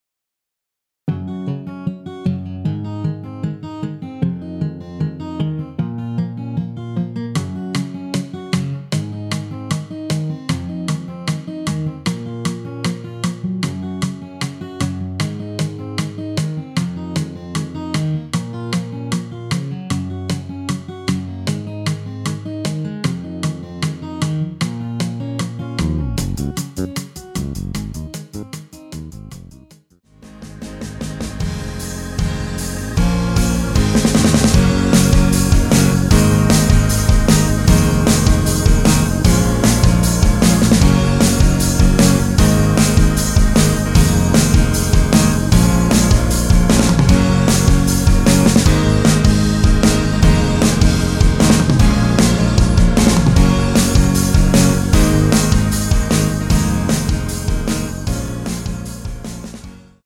원키에서(-1)내린 MR입니다.
Bbm
앞부분30초, 뒷부분30초씩 편집해서 올려 드리고 있습니다.
중간에 음이 끈어지고 다시 나오는 이유는